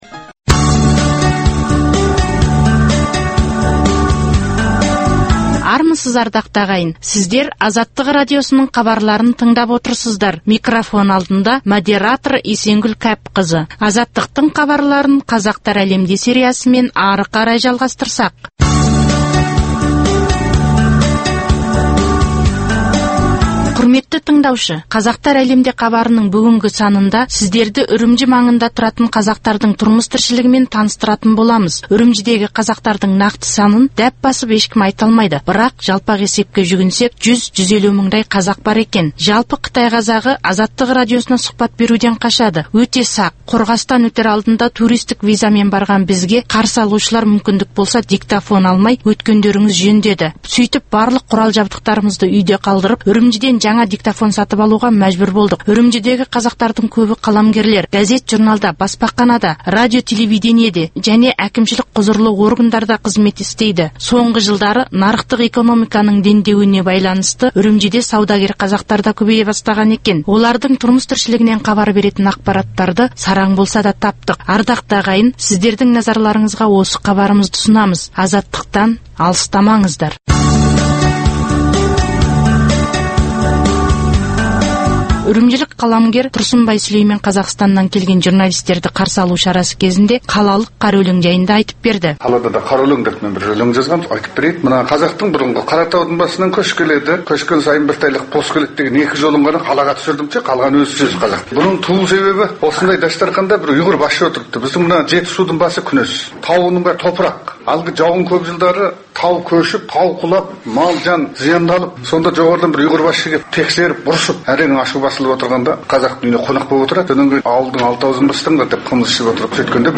Хабарда жергілікті қазақтардың ән-жыры мен күйлерін де тыңдай аласыз.